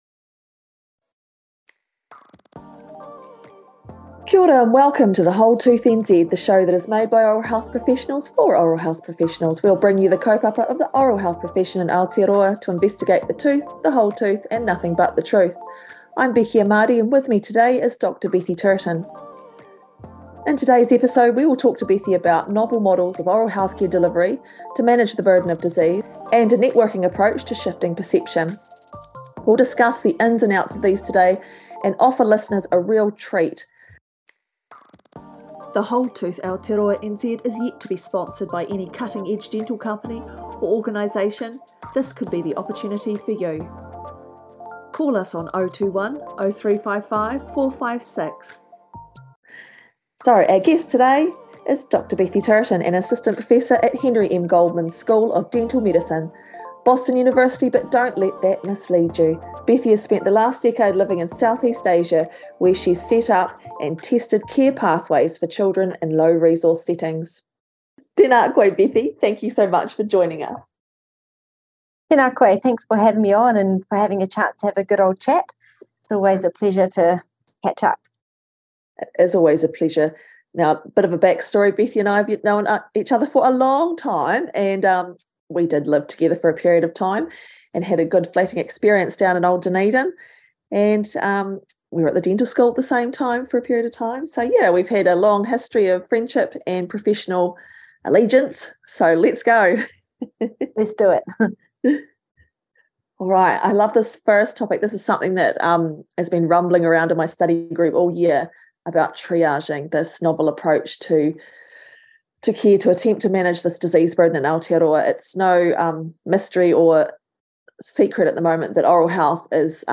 Sit back, relax and enjoy this stimulating conversation.